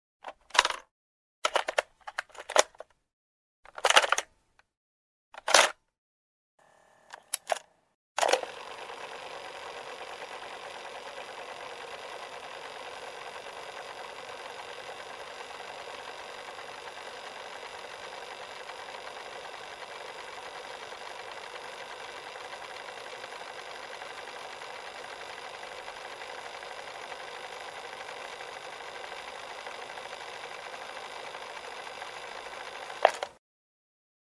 На этой странице собраны звуки магнитофона: шум ленты, щелчки кнопок, запись с кассет.